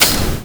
puff.wav